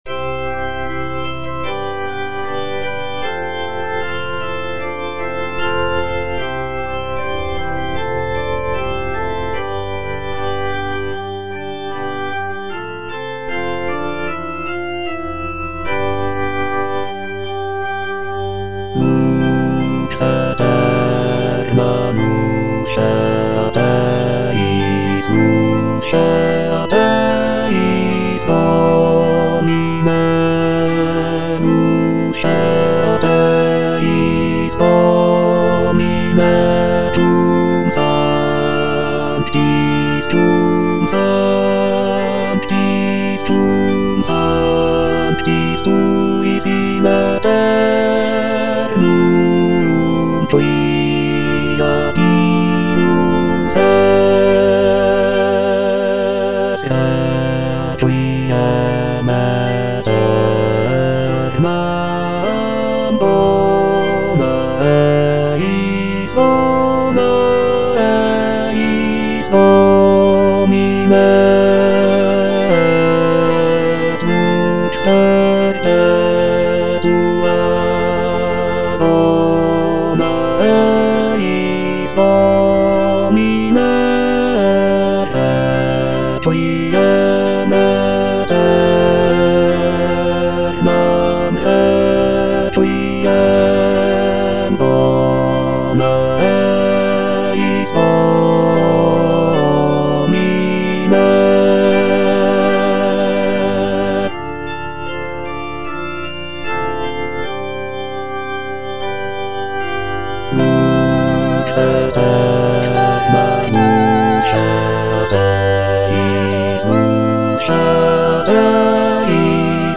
(bass II) (Emphasised voice and other voices) Ads stop
is a sacred choral work rooted in his Christian faith.